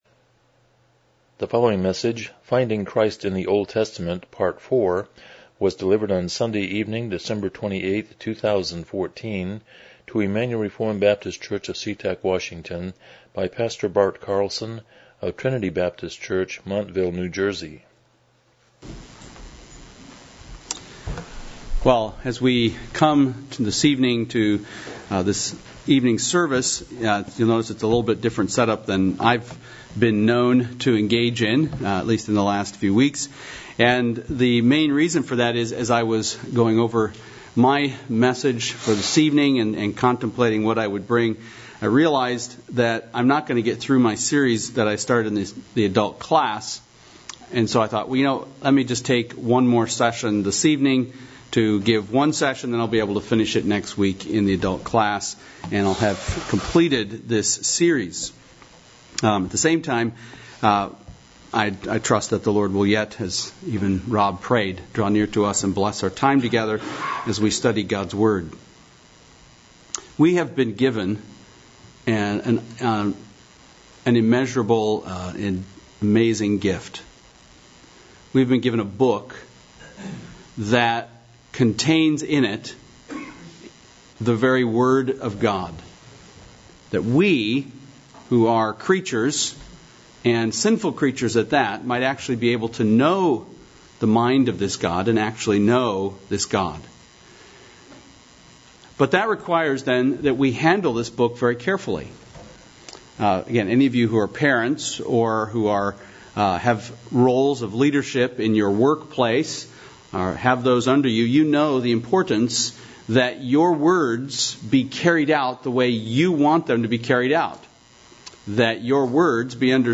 Miscellaneous Service Type: Evening Worship « Live or Die?